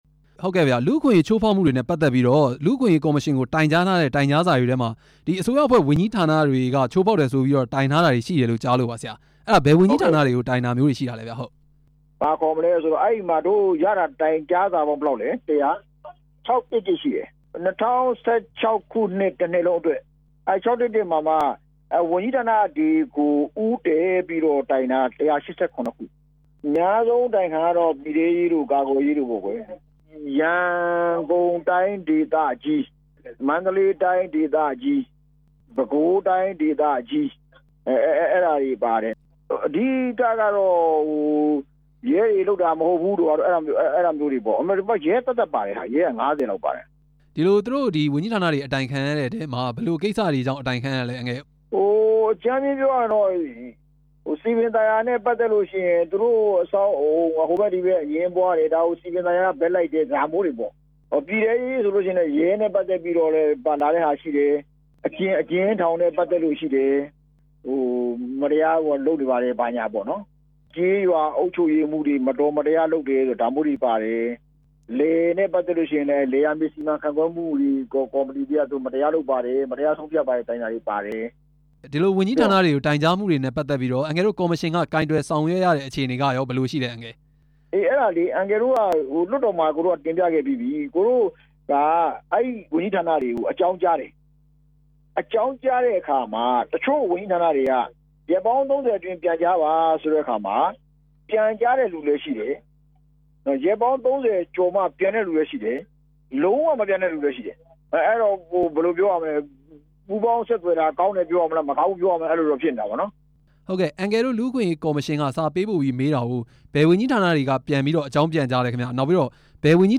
လူ့အခွင့်အရေးကော်မရှင် အဖွဲ့ဝင် ဦးယုလွင်အောင် နဲ့ မေးမြန်းချက်